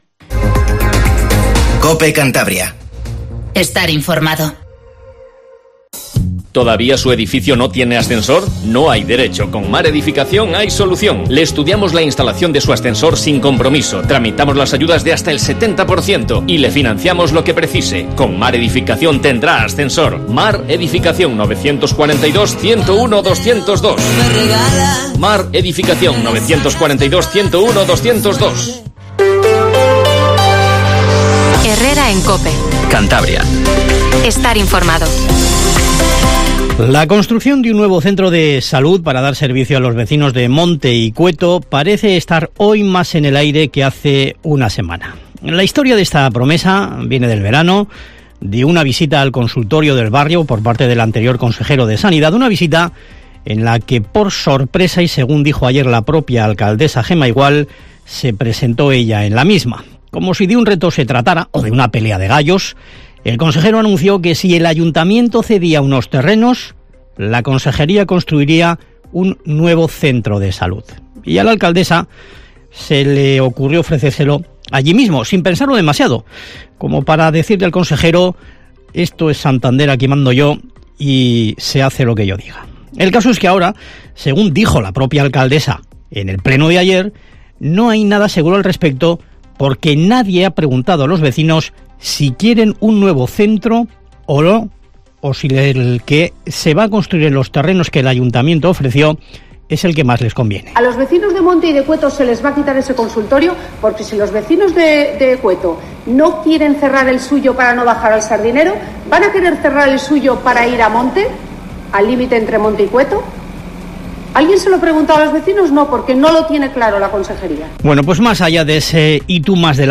Informativo HERRERA en COPE CANTABRIA 07:50